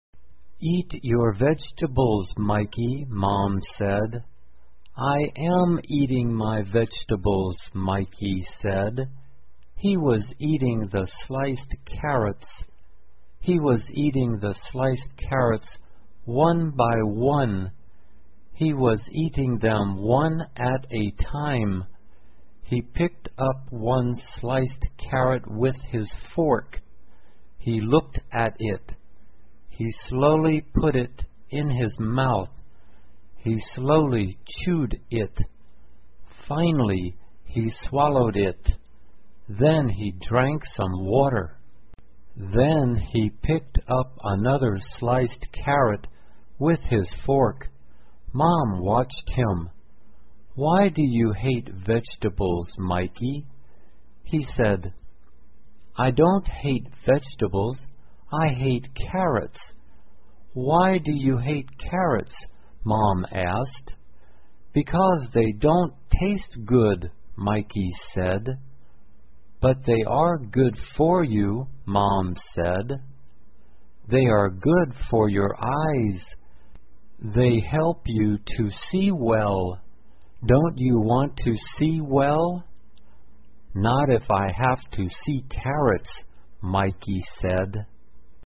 慢速英语短文听力 讨厌胡萝卜 听力文件下载—在线英语听力室